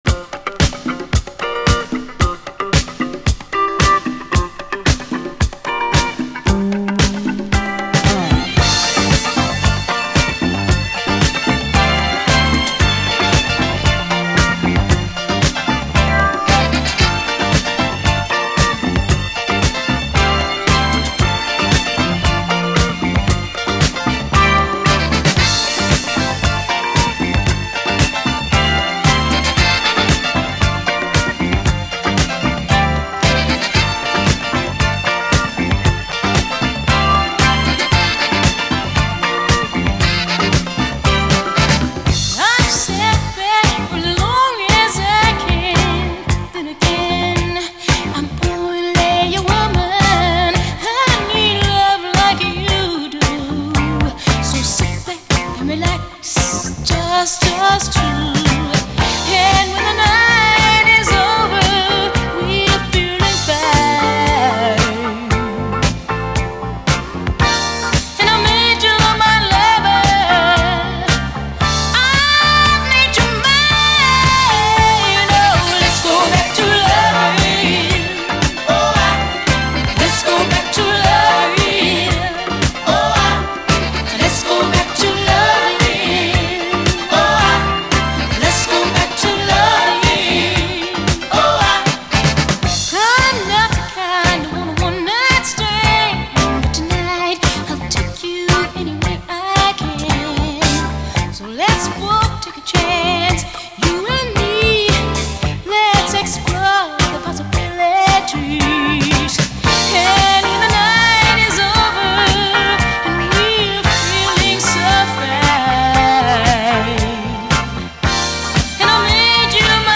SOUL, 70's～ SOUL, DISCO, 7INCH